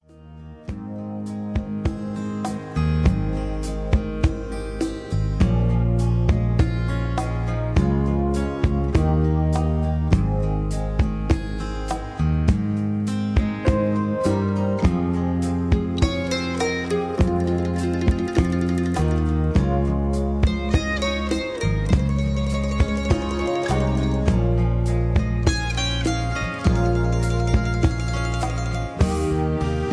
(Key-Ab) Karaoke Mp3 Backing Tracks
karaoke